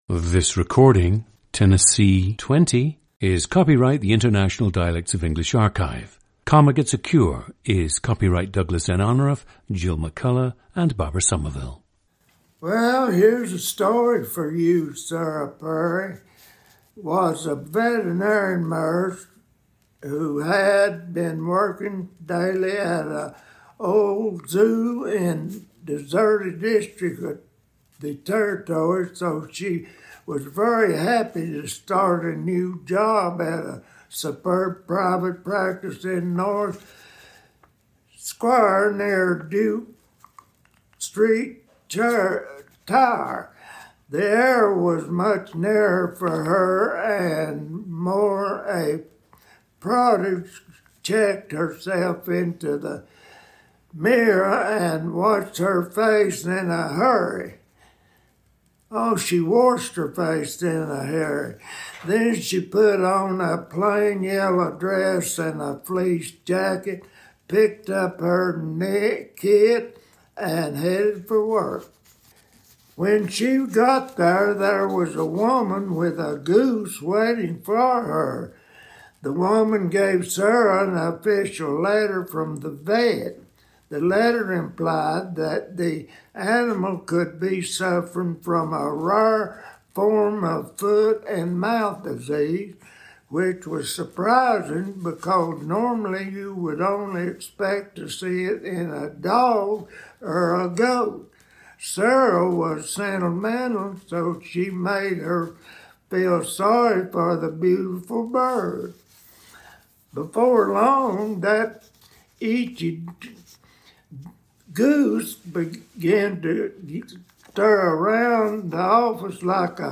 GENDER: male
The subject had some difficulty reading Comma Gets a Cure, which accounts for his skipping certain passages and his mispronunciation of certain words, such as “come on” instead of “Comma.”
• Recordings of accent/dialect speakers from the region you select.
The recordings average four minutes in length and feature both the reading of one of two standard passages, and some unscripted speech.